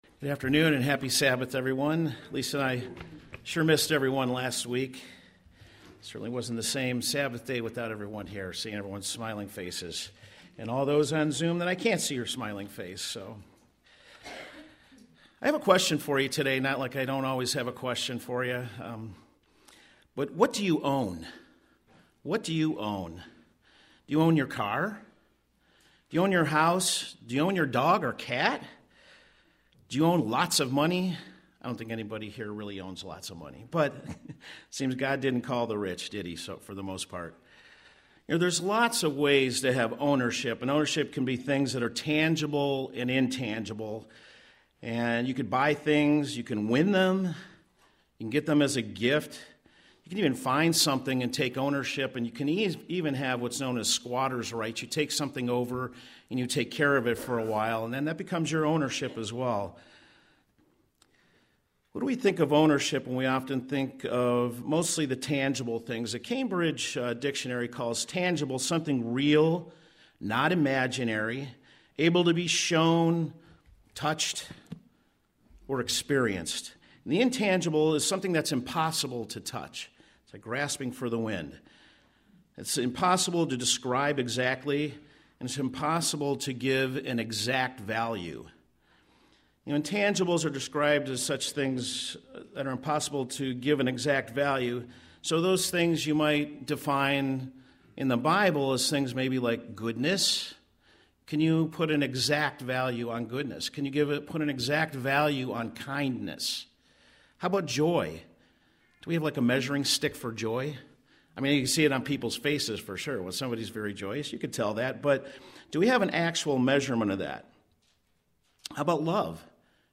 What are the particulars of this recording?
Given in Cleveland, OH